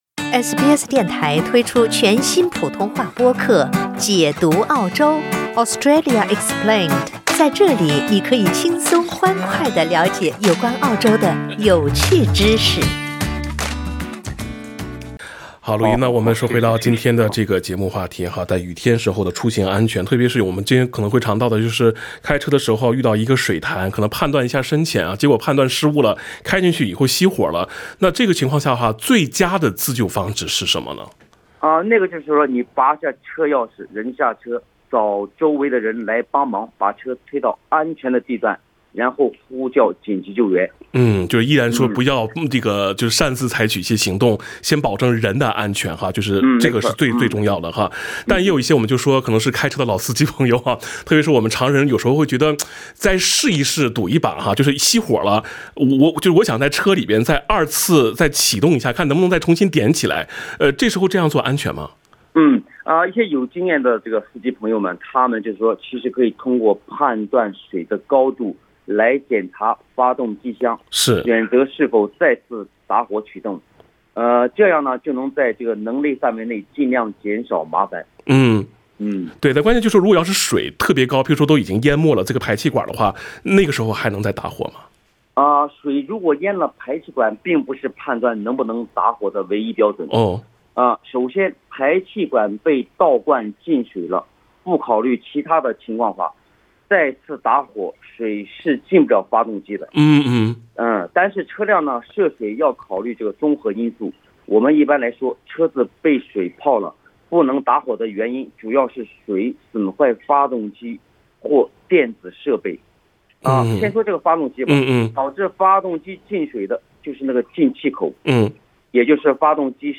听众热线